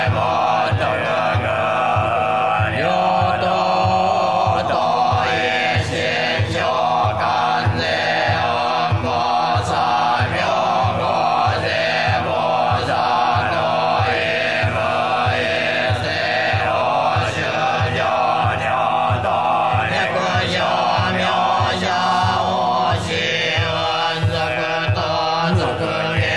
臨済宗 檀信徒勤行 カセット
大本山妙心寺法務部監修・読誦によるお経カセット (宗紋入り)。